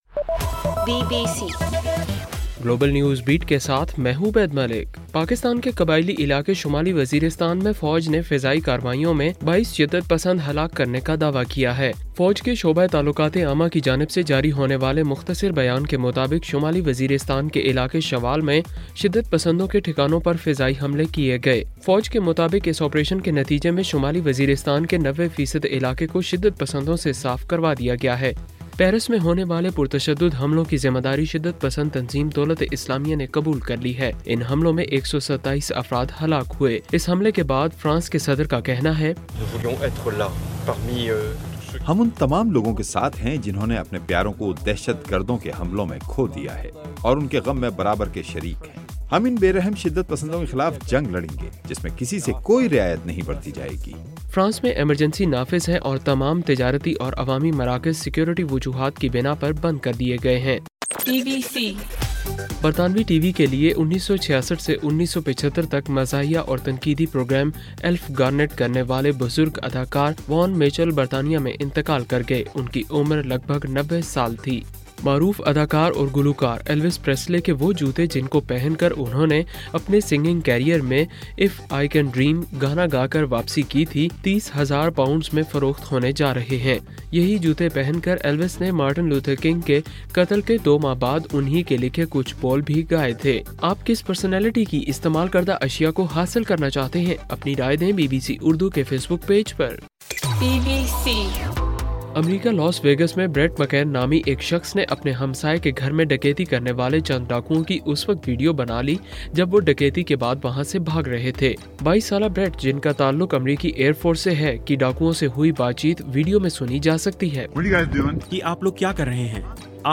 نومبر 14: رات 12 بجے کا گلوبل نیوز بیٹ بُلیٹن